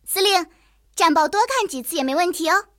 野牛查看战绩语音.OGG